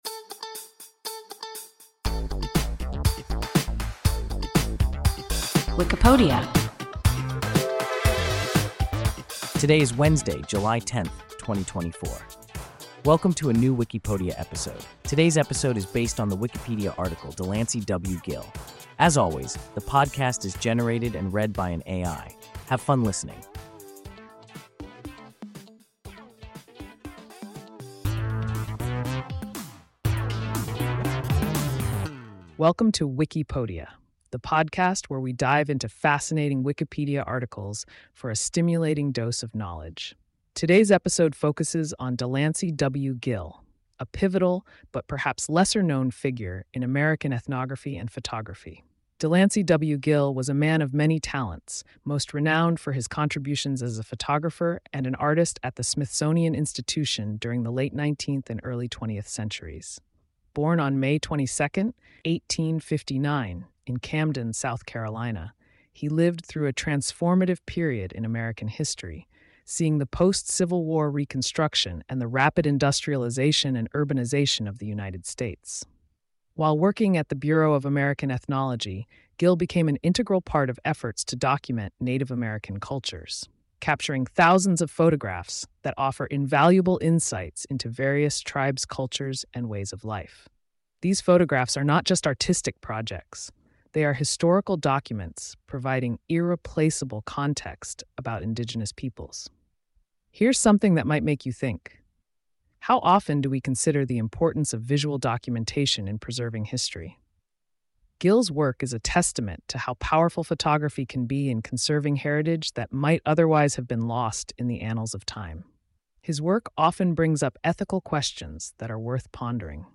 DeLancey W. Gill – WIKIPODIA – ein KI Podcast